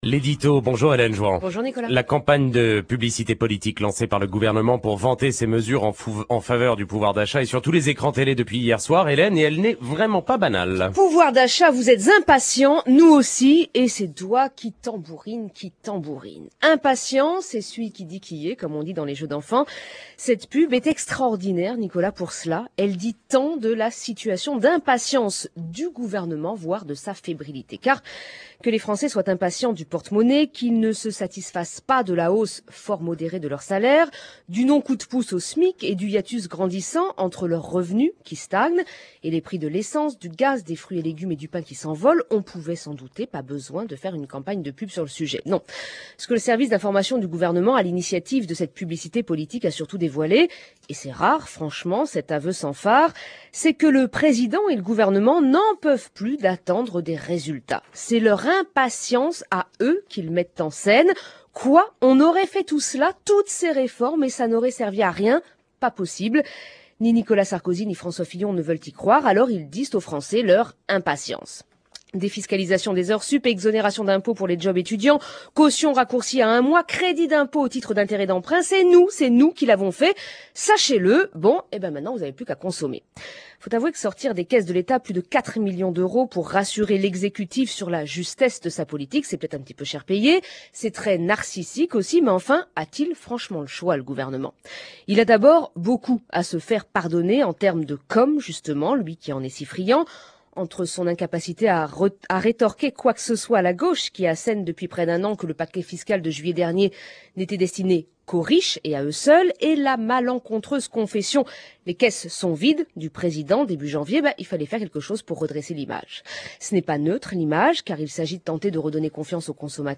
Pub pour le pouvoir d’achat : un pari risqué Campagne de publicité politique – l’édito